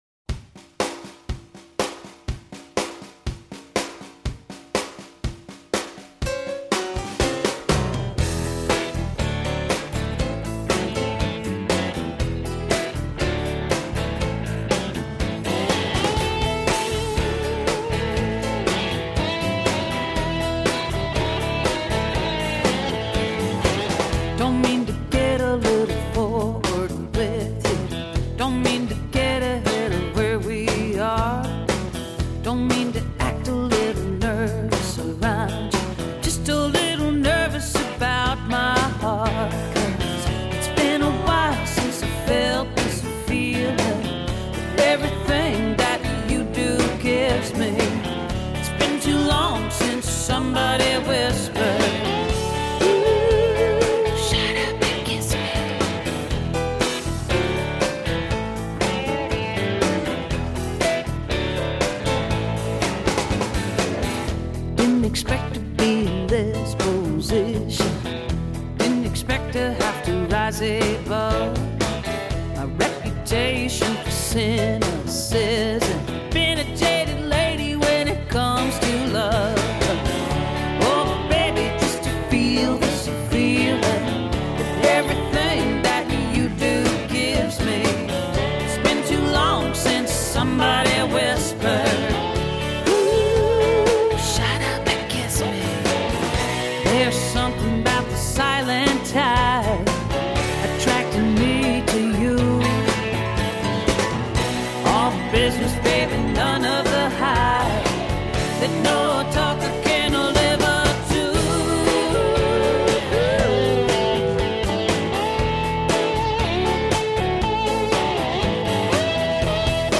Genre: Country.